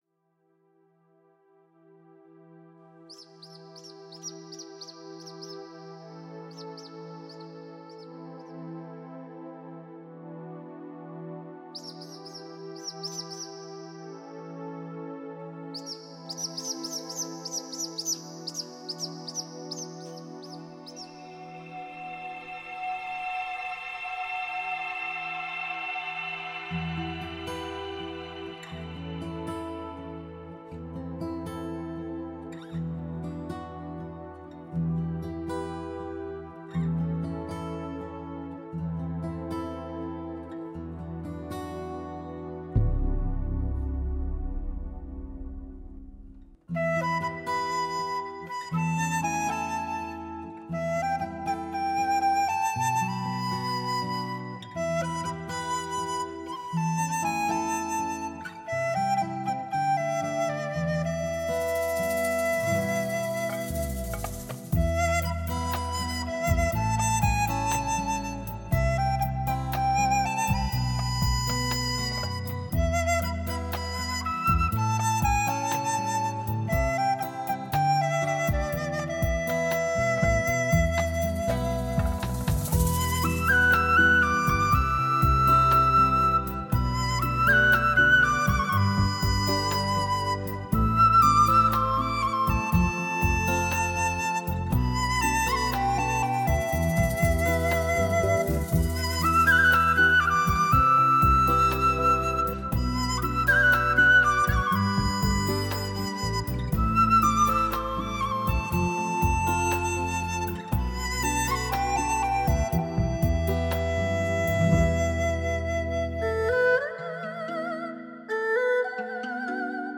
DTS-ES6.1多声道环绕音乐
银铃样悦耳,充满了东方神韵。
这张专辑制作精湛，音质上是无可挑剔的。
最优美的草原风情音乐诗画。